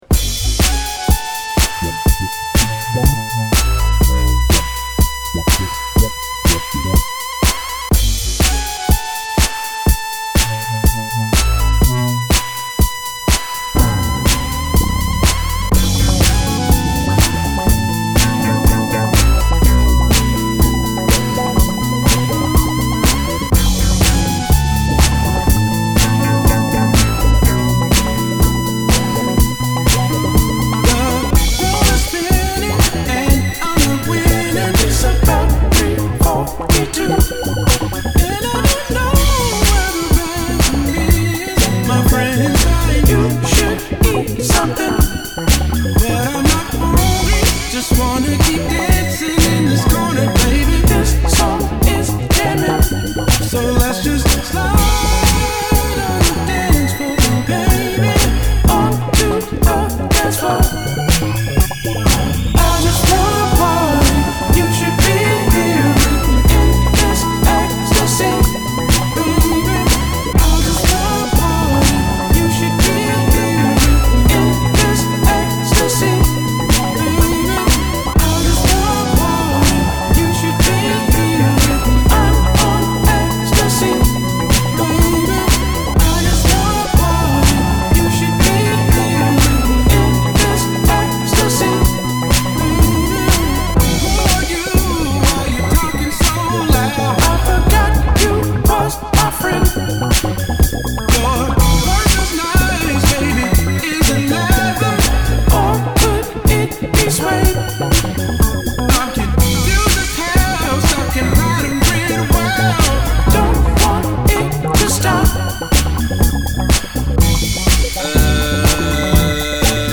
This vivid, psychedelic synth-funk jam